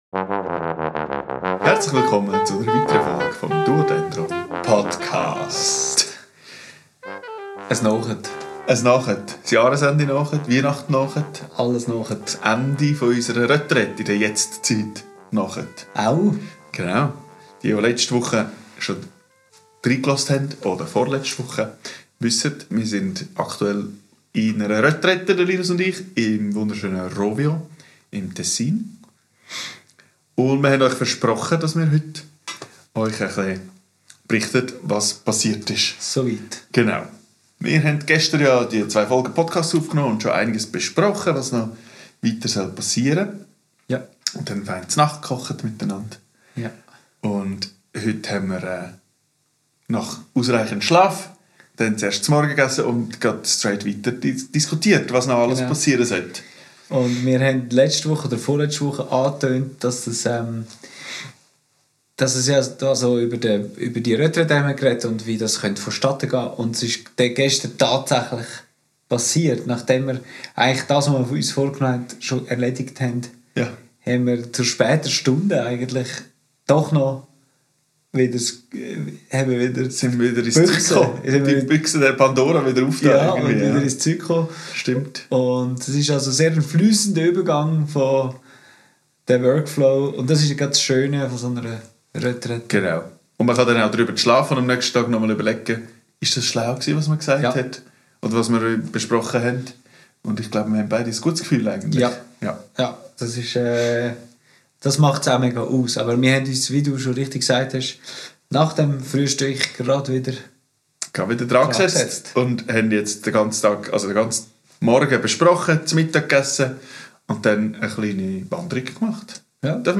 Und natürlich darf auch ein letztes Weihnachtslied nicht fehlen.
Aufgenommen am 04.12.2025 in Rovio.